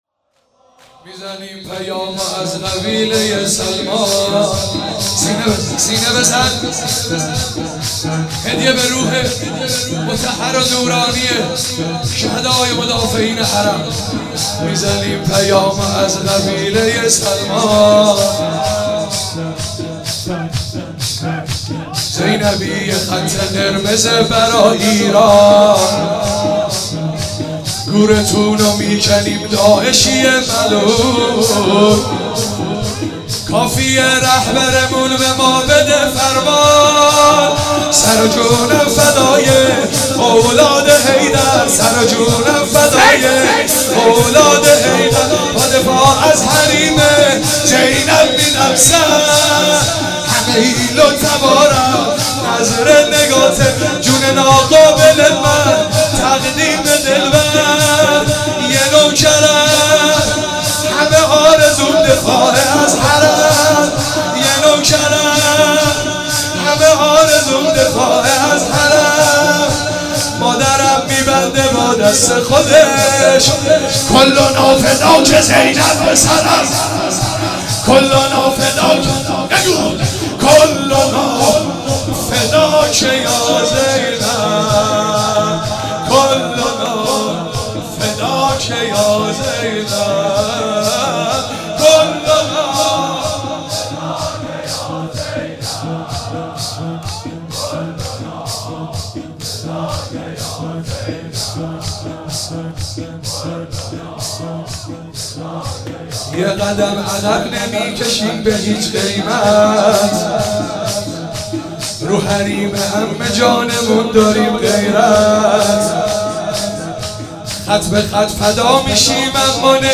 مراسم هفتگی 24 فروردین 96
چهاراه شهید شیرودی حسینیه حضرت زینب (سلام الله علیها)